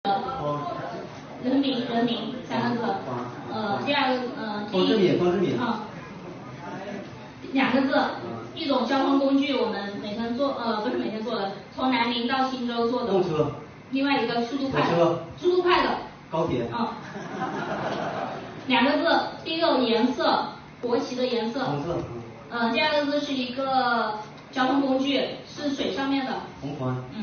第三个环节是趣味竞答，你来比划我来猜，每轮120秒，每队由2名队员参与答题，其中1人根据提示内容进行解释，另1人根据解释猜出题目内容。